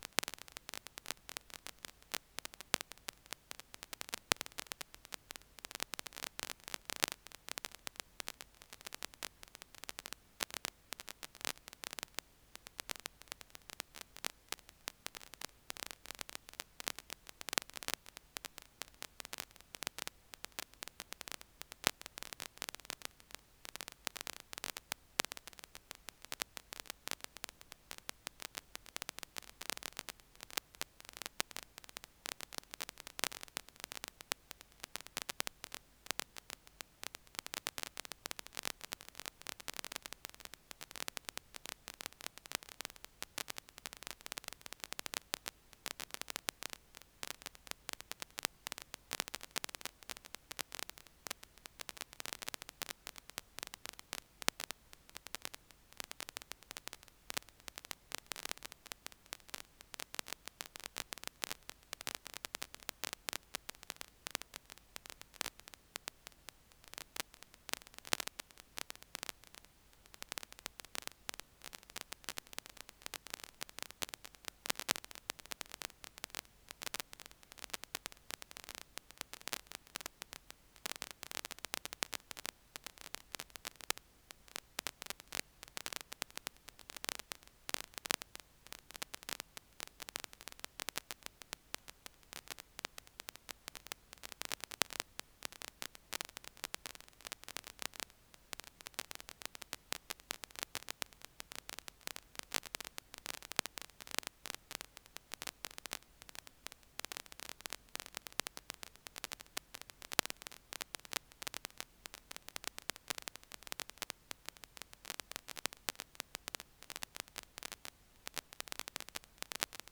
Archivo de audio de prueba para ThereminoMCA
Debido a su poca longitud, y también revisar el archivo varias veces, permanece siempre ruidoso, Sería necesario archivo de al menos diez minutos.